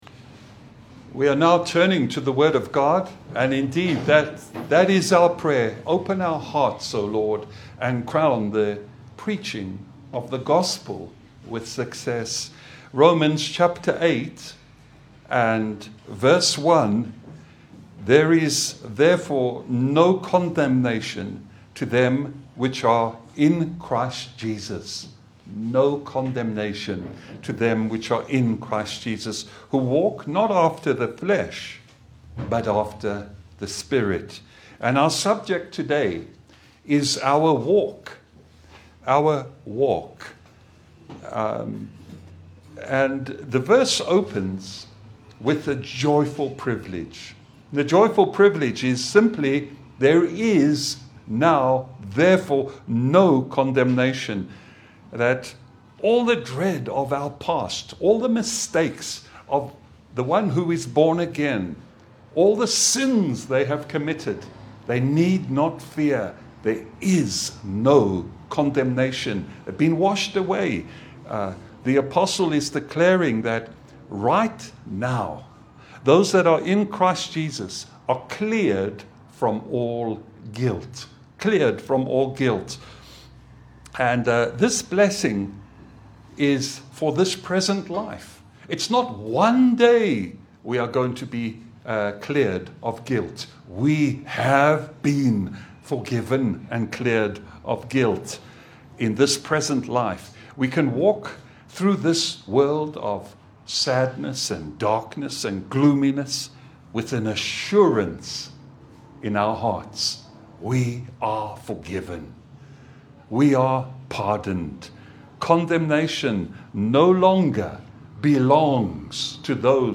Service Type: Gospel Service